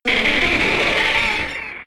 Cri de Parasect K.O. dans Pokémon X et Y.